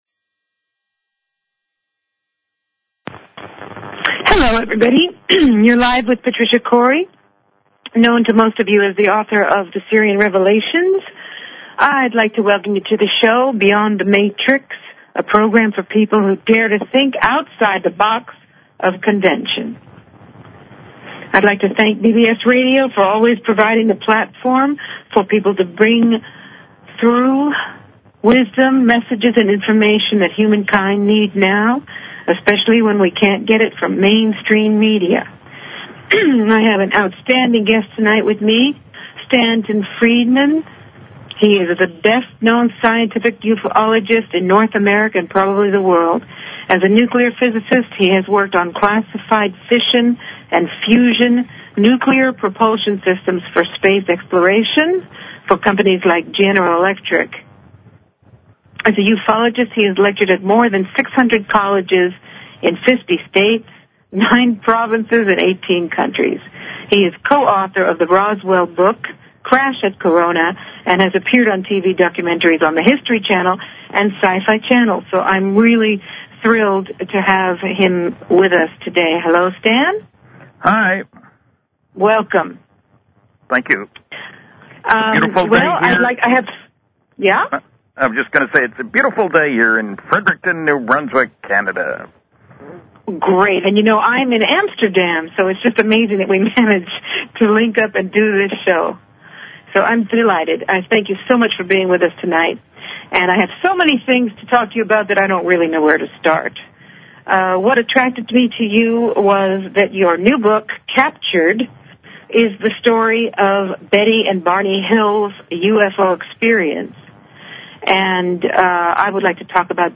Talk Show Episode, Audio Podcast, Beyond_The_Matrix and Courtesy of BBS Radio on , show guests , about , categorized as
Beyond the Matrix is thrilled to bring on Stanton Friedman for an invigorating and daring conversation about UFOs. Mr. Friedman is the best-known scientific UFOlogist in North America, and perhaps the world.